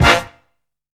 BREAKIN HIT.wav